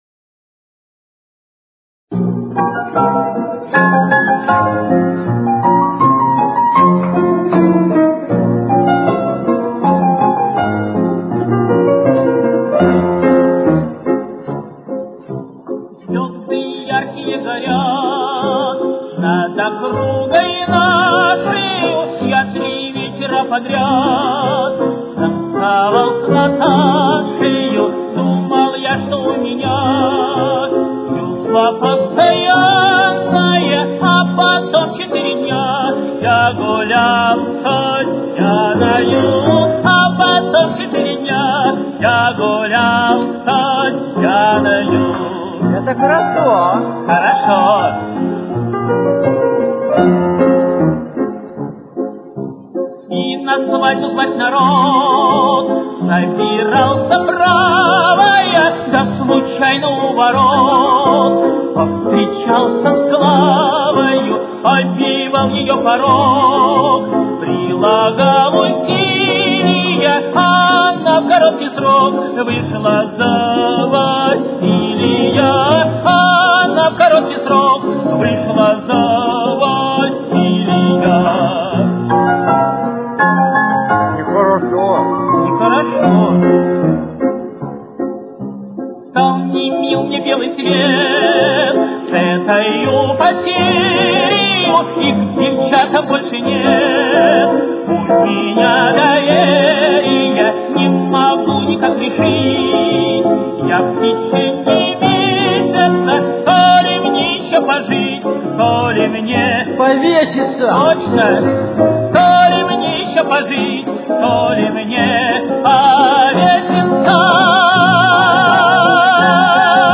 Темп: 110.